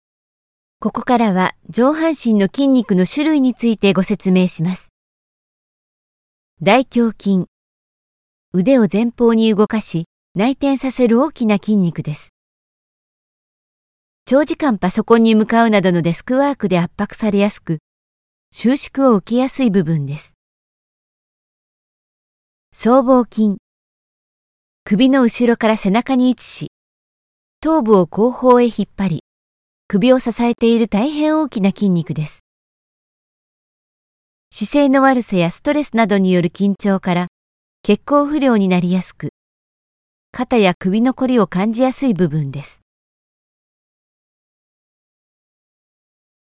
Comercial, Versátil, Seguro, Empresarial, Joven
E-learning